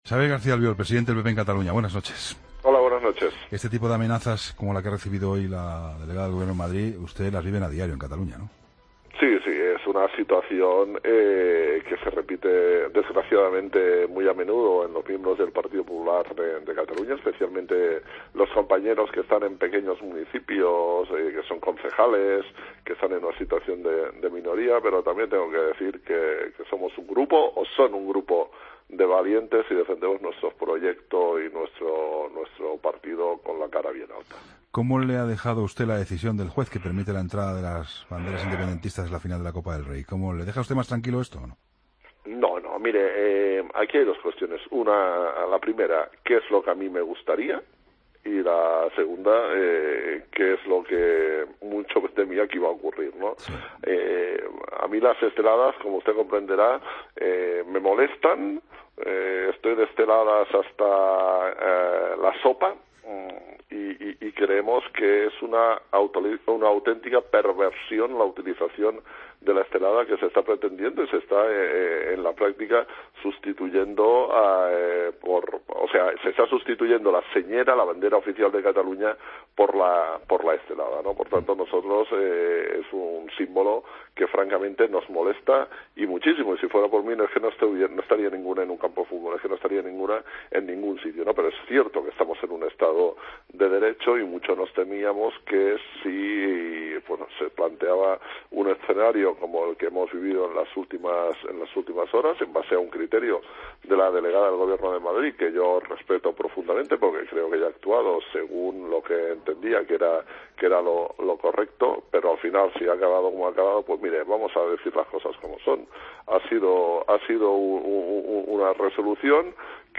Xabier García Albiol, presidente del PP en Cataluña, en La Linterna